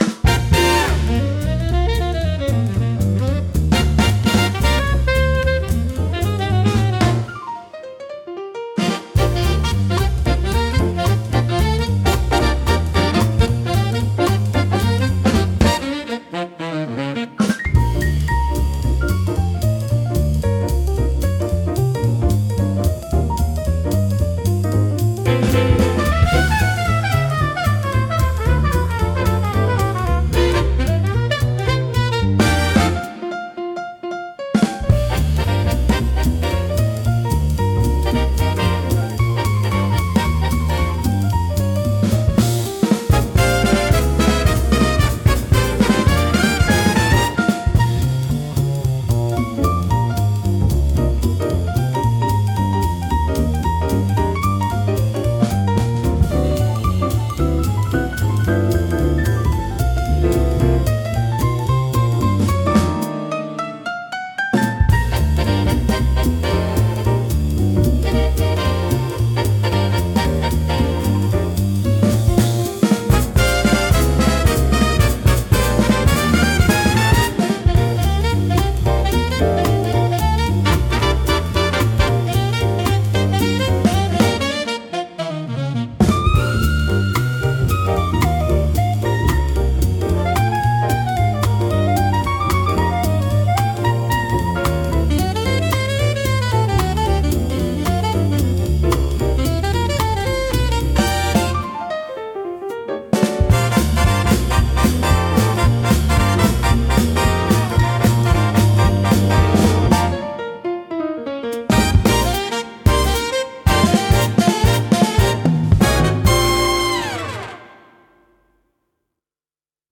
聴く人を楽しい気分にさせる迫力とノリの良さが魅力のジャンルです。